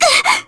Yanne_L-Vox_Damage_jp_04.wav